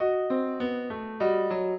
piano
minuet8-3.wav